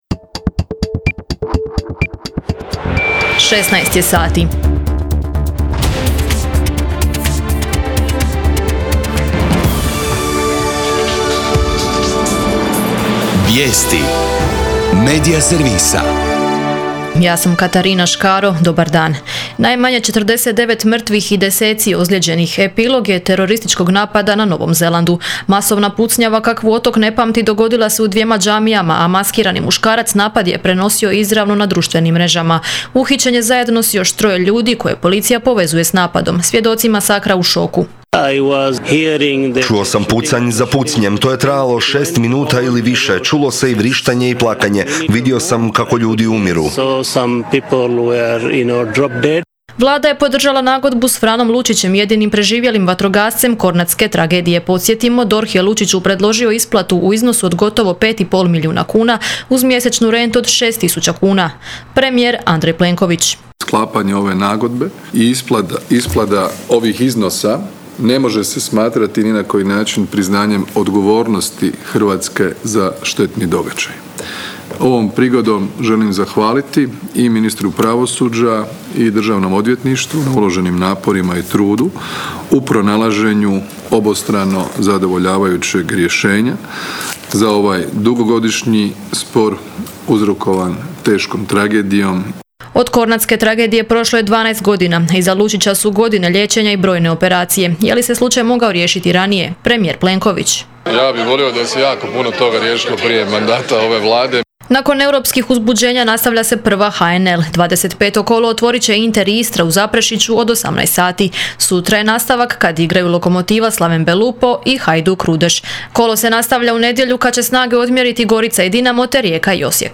VIJESTI U 16